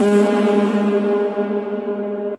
High Pitch Fart Reverb Sound Button - Free Download & Play